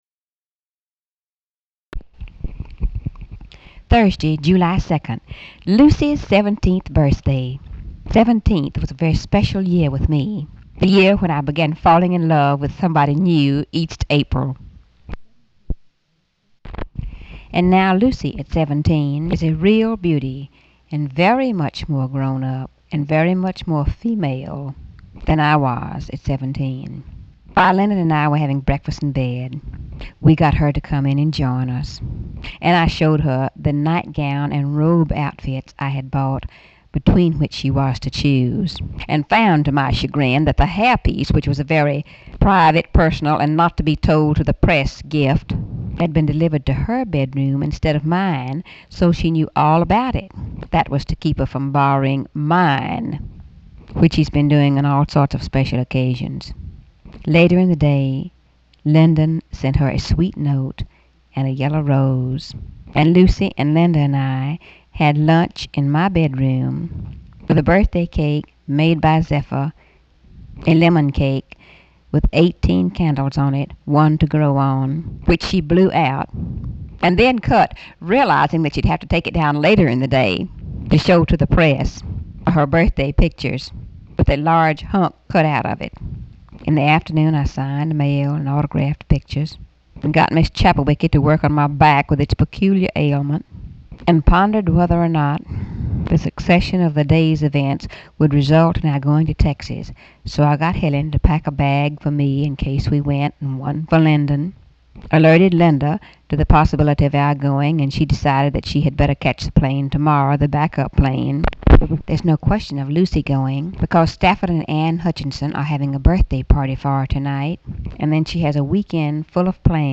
Audio diary and annotated transcript, Lady Bird Johnson, 7/2/1964 (Thursday) | Discover LBJ